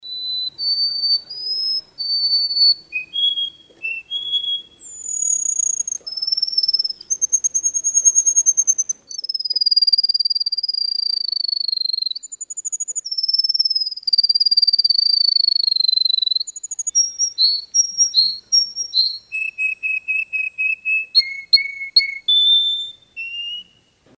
Канарейка среди лесных деревьев